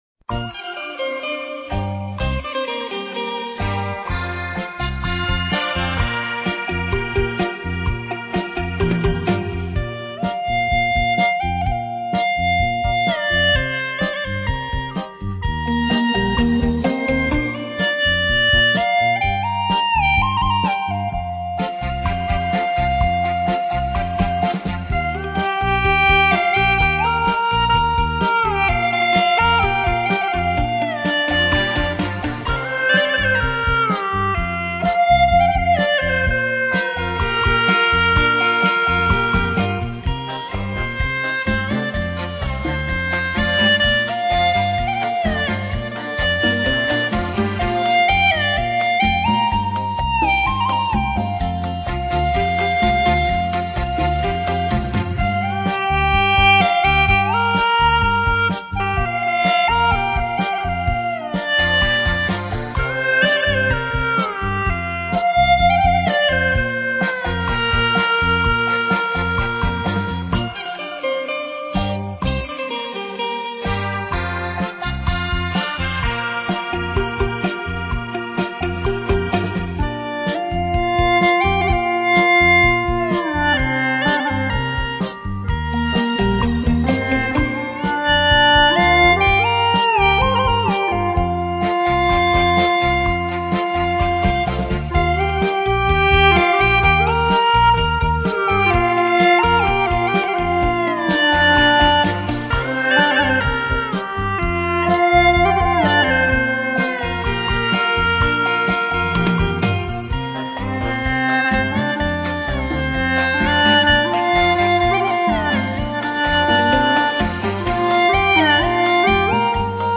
调式 : 降E筒音1 曲类 : 流行